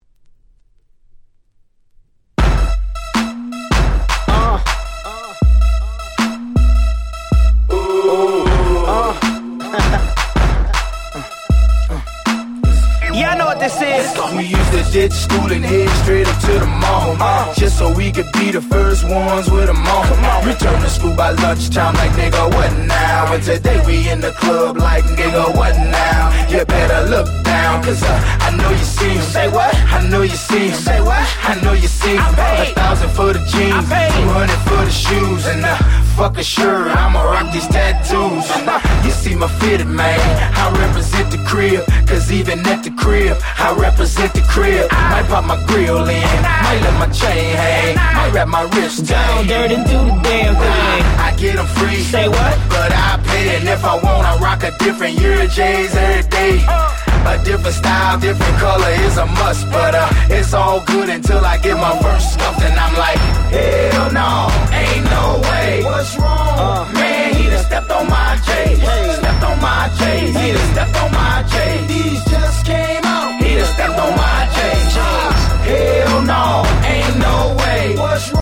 08' Super Hit Hip Hop/R&B♪